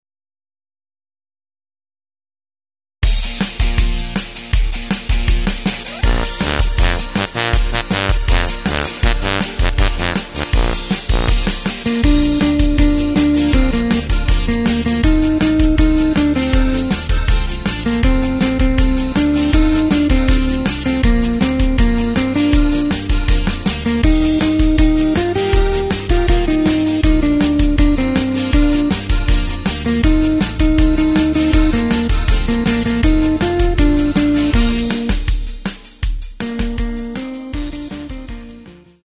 Takt:          4/4
Tempo:         160.00
Tonart:            Bb
Rock`n Roll aus dem Jahr 2008!